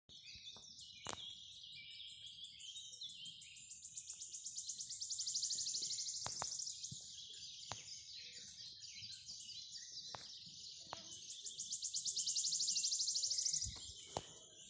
Wood Warbler, Phylloscopus sibilatrix
StatusSinging male in breeding season